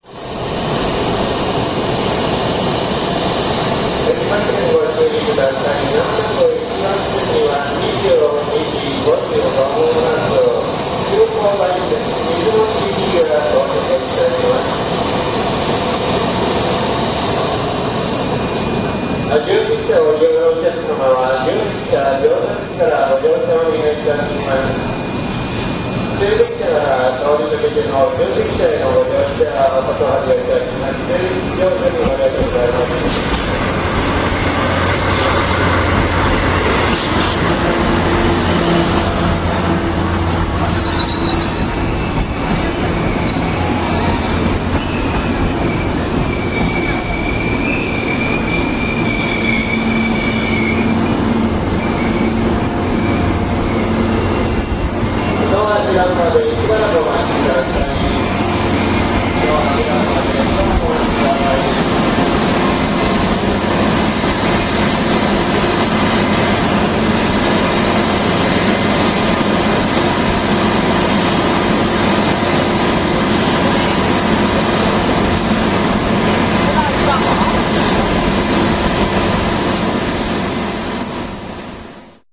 大阪駅1番ホームに急行だいせんが入線してくる時の録音です。
"どしん、ぱしゃん、どしん"は、B-2-Bの軸配置を持ったDD51特有のジョイント音。大きな軸重が許される東海道本線では、中間の従台車にかかる荷重は少なく、跳ねるような音をたてています。
そのあとに続くのは、5両の客車がブレーキを軋ませながら停車する様子。最後にずっと聞こえているのは、最後尾の5号車・スハフ12の発電用エンジンの音です。